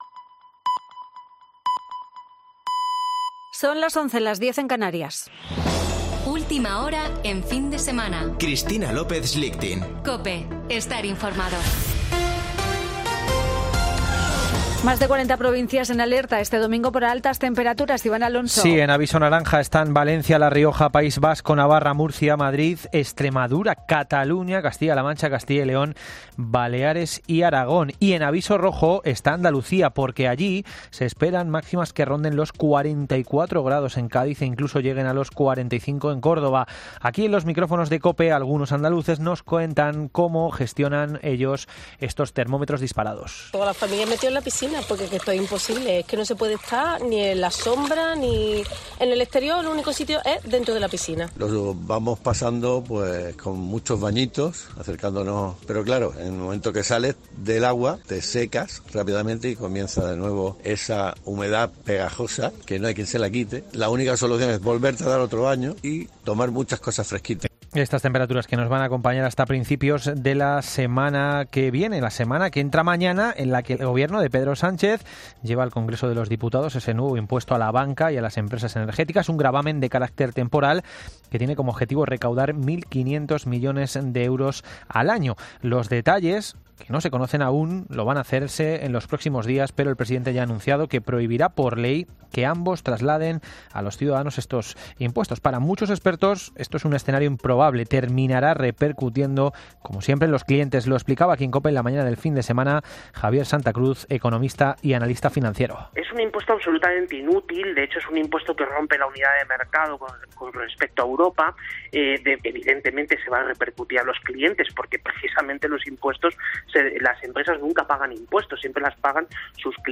Boletín de noticias de COPE del 24 de julio de 2022 a las 11:00 horas